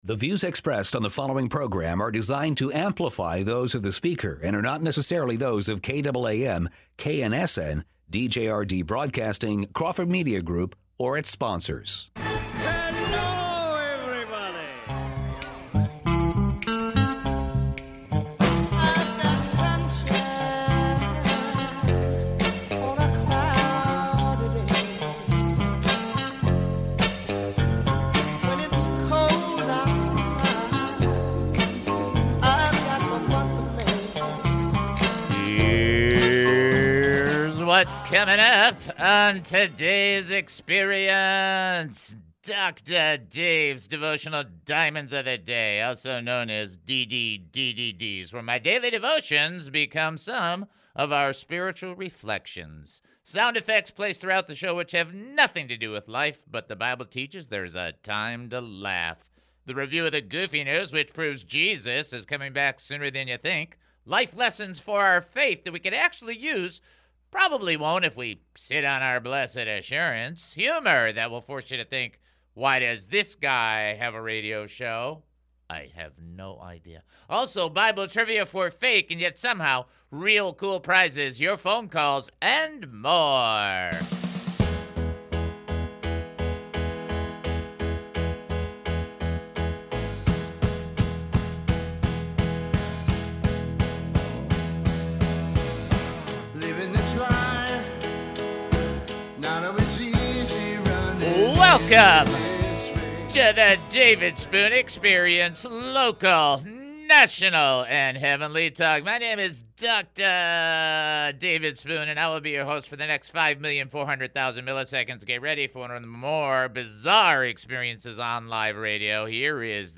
C) Then prayers are offered for various people as well as praises, plus an in-depth prayer for people who don't know Jesus and confess him as their Lord and Savior.